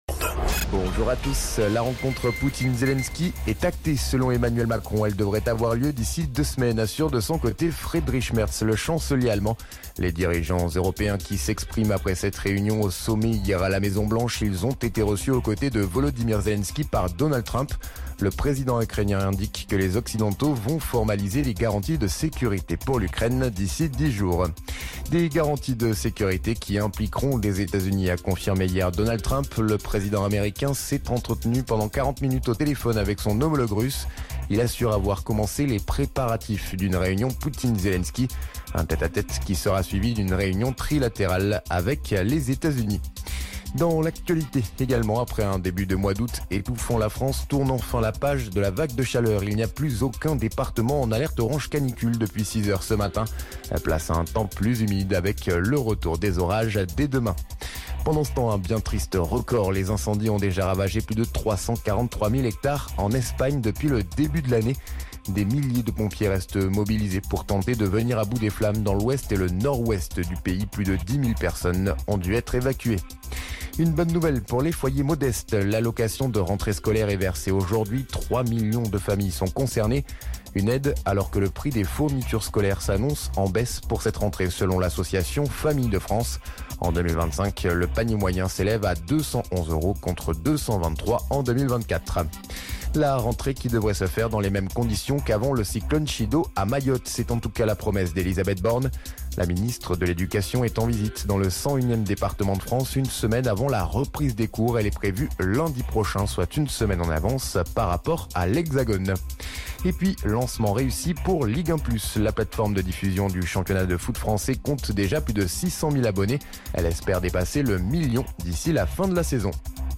Flash Info National 19 Août 2025 Du 19/08/2025 à 07h10 .